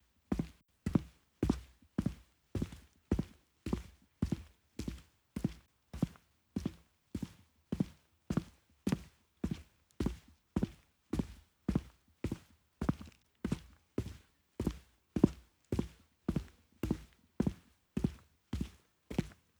Footstep Samples